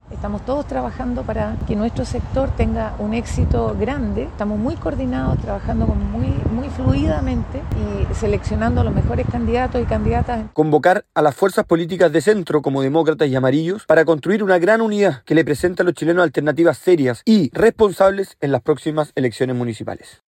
En tanto, la presidenta de Evópoli, Gloria Hutt, y el jefe de bancada de esa colectividad, el diputado Jorge Guzmán, hicieron un llamado a agilizar las negociaciones con Demócratas y Amarillos.